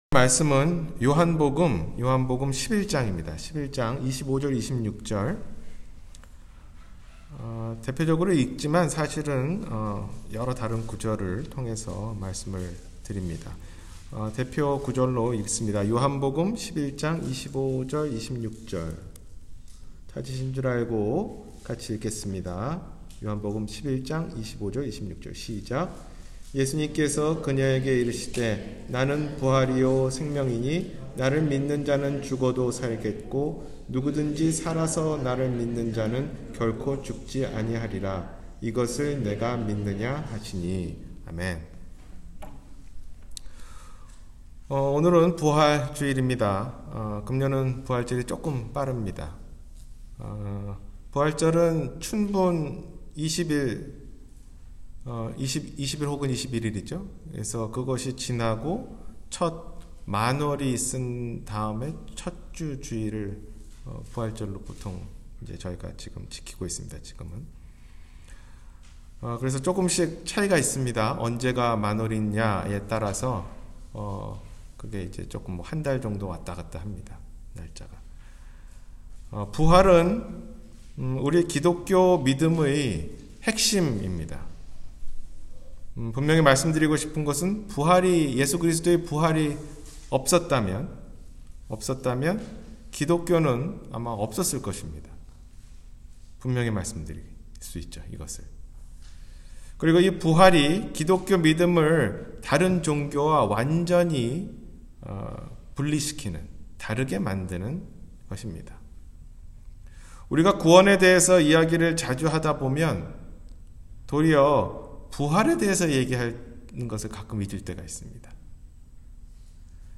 예수 그리스도의 부활 사건이 갖는 의미 – 주일설교